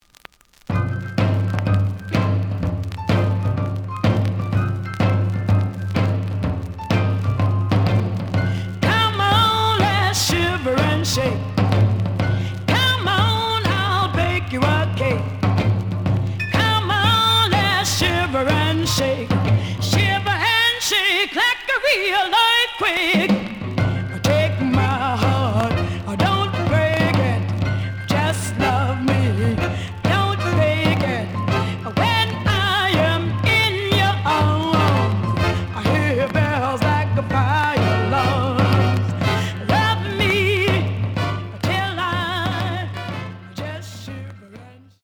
The audio sample is recorded from the actual item.
●Genre: Rhythm And Blues / Rock 'n' Roll
Some click noise on B side due to scratches.